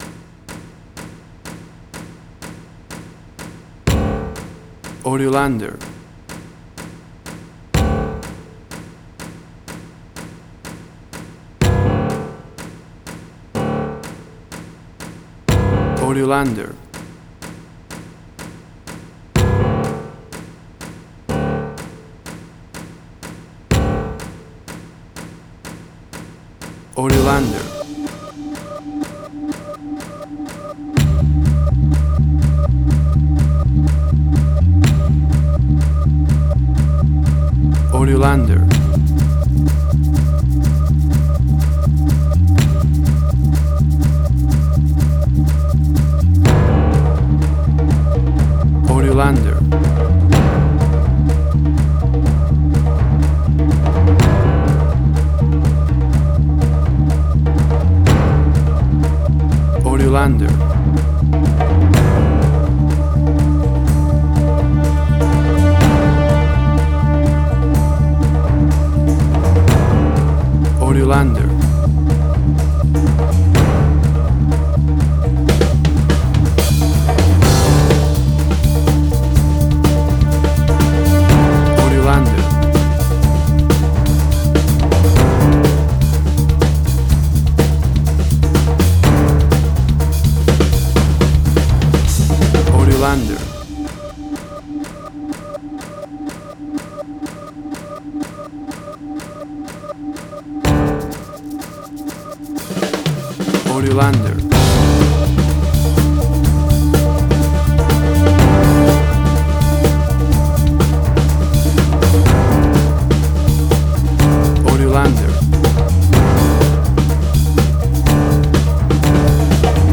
Suspense, Drama, Quirky, Emotional.
Tempo (BPM): 124